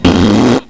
raspberr.mp3